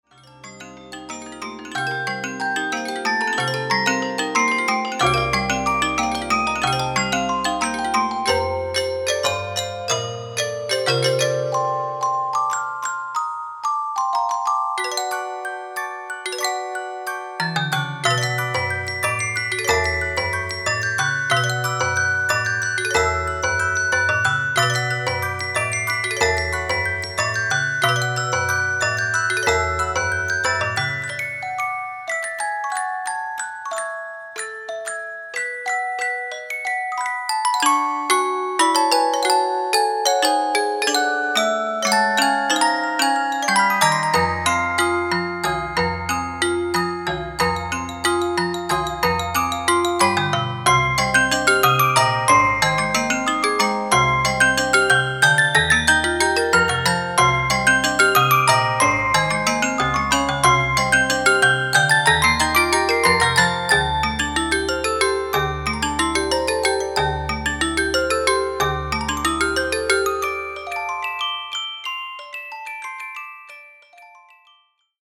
※再生中にノイズや歪みのように聴こえる箇所がありますが、